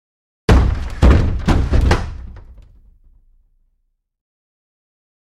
На этой странице собраны разнообразные звуки сундуков: скрип дерева, стук металлических застежек, глухой гул пустого пространства внутри.
Скатился вниз по лестнице